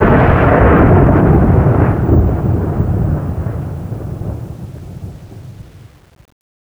thunder3.wav